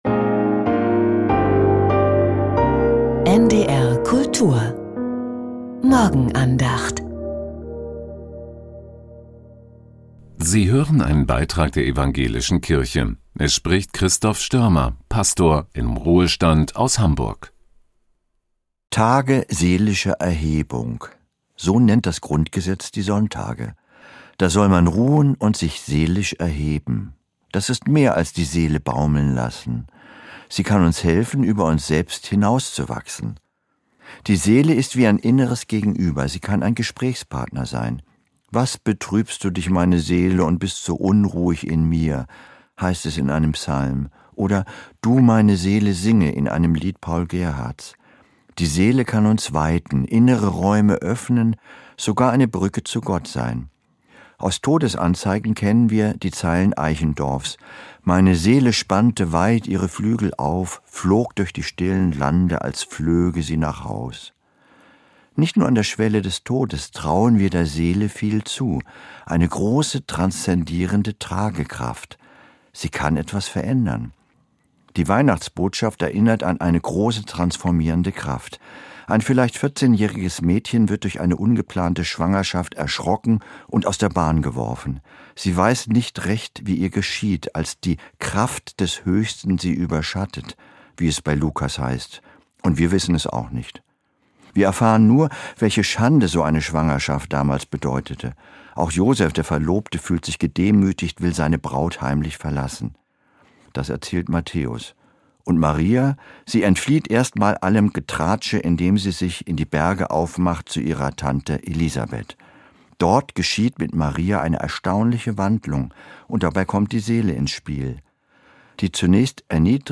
Tage seelischer Erhebung ~ Die Morgenandacht bei NDR Kultur Podcast